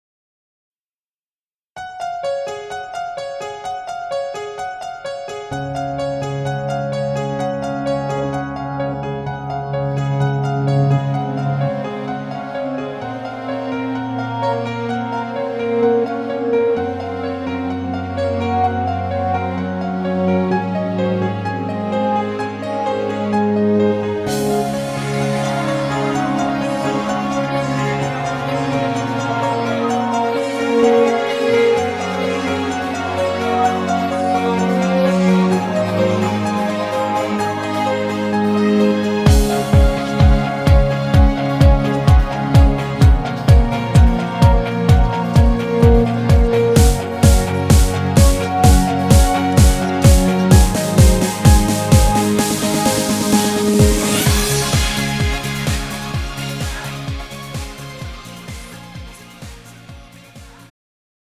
음정 원키
장르 구분 Pro MR